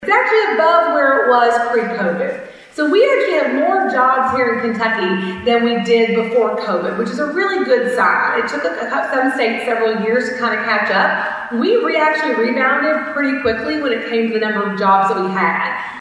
Thursday morning’s Christian County Chamber of Commerce Legislative Eye Opener Breakfast at the Silo Events Center brought one significant topic into focus.